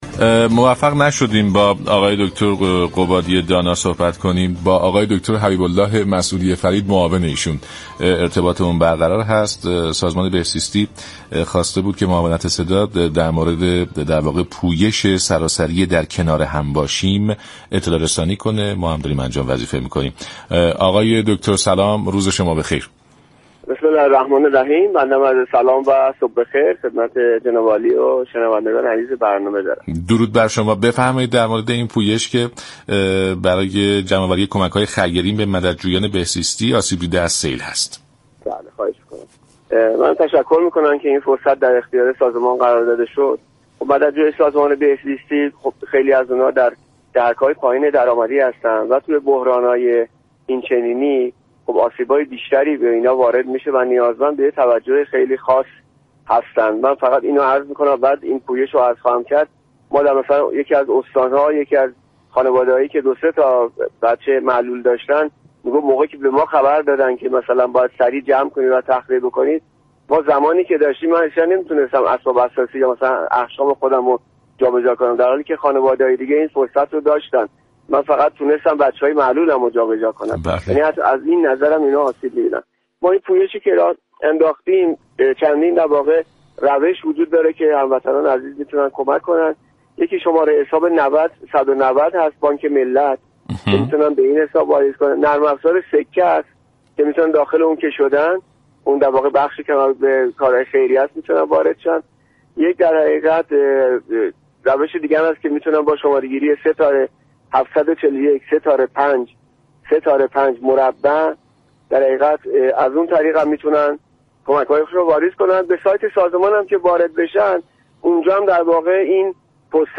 دكتر حبیب الله مسعودی فرید معاون سازمان بهزیستی كشور در برنامه سلام صبح بخیر رادیو ایران گفت : مردم می توانند در بهزیستی های استان های مختلف وسایل و نیازهای سیل زدگان را ارائه كنند